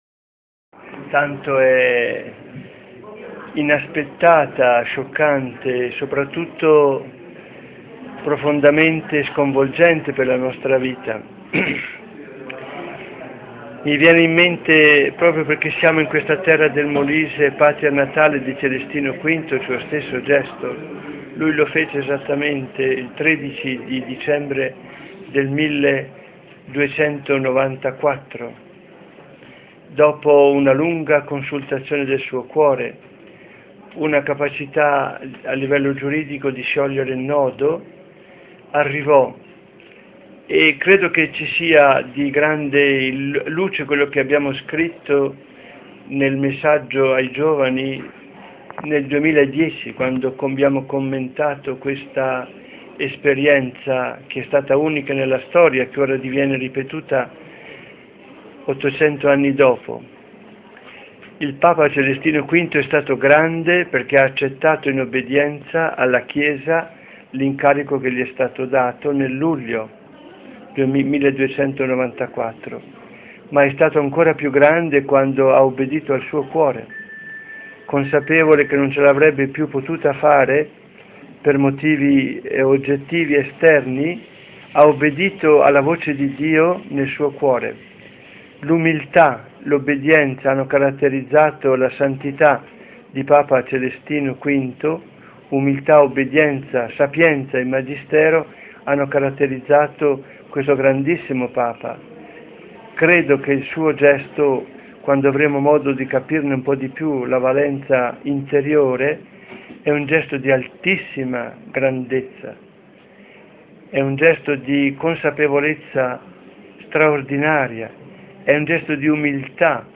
PADRE GIAN CARLO BREGANTINI ARCIVESCOVO METROPOLITA DI CAMPOBASSO-BOJANO APPRENDE LA NOTIZIA INATTESA E COMMENTA.